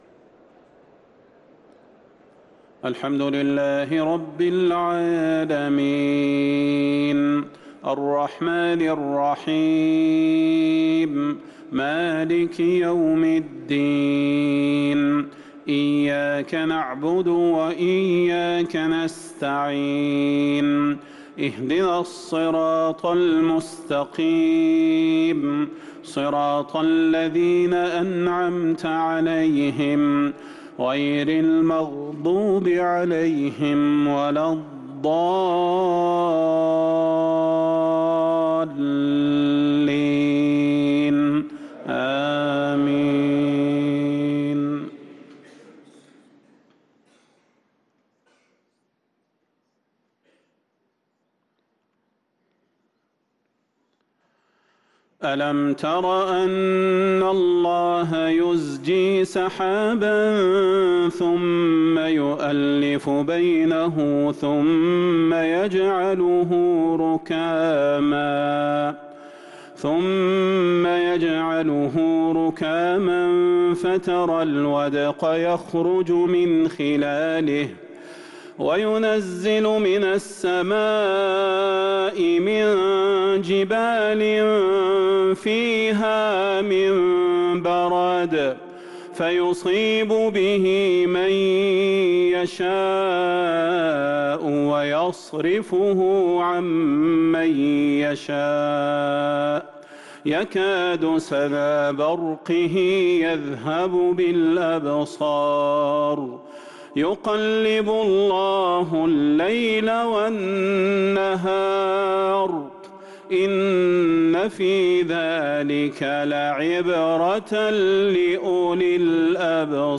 صلاة المغرب للقارئ صلاح البدير 17 ربيع الآخر 1444 هـ